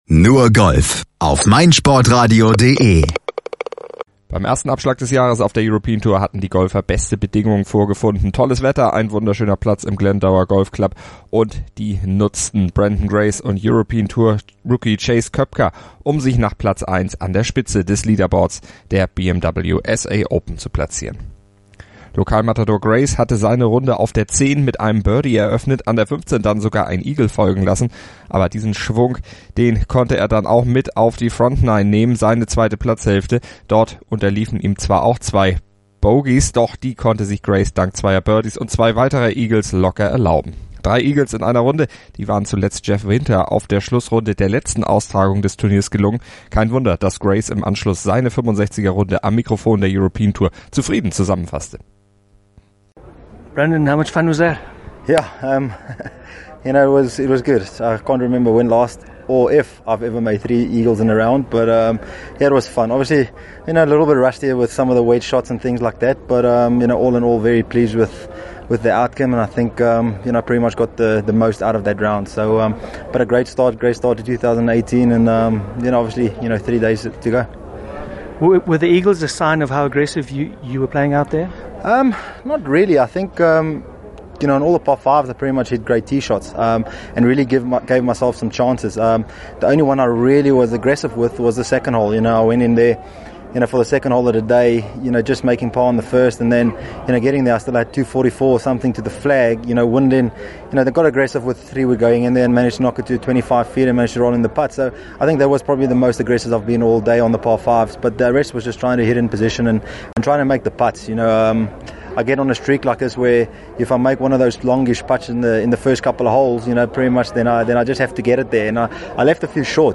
Spitzenreiter in Interviews mit der European Tour.